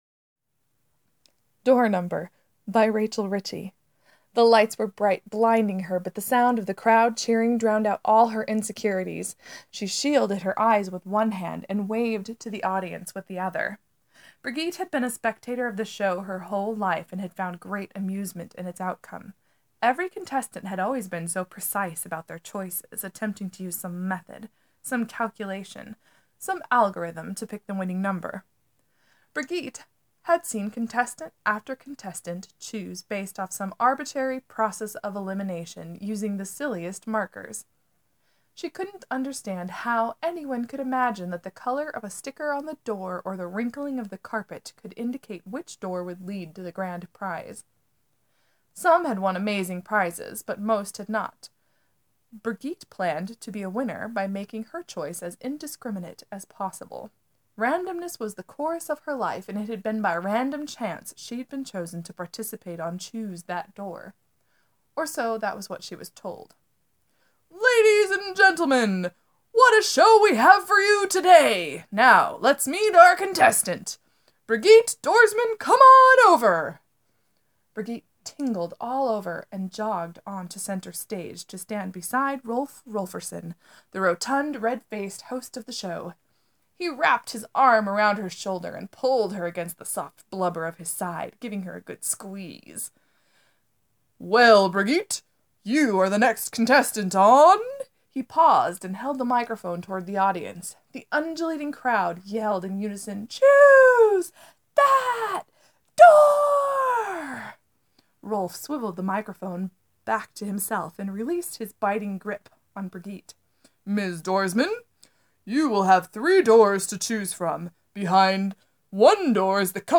There are one or two small liptrips but otherwise here’s a silly recording of the story!